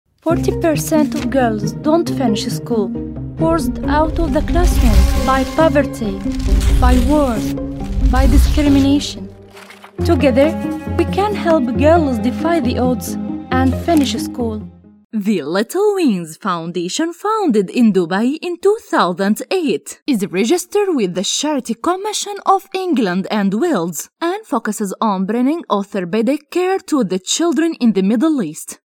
Arabic (Egypt), Middle Eastern, Female, Studio, 20s-40s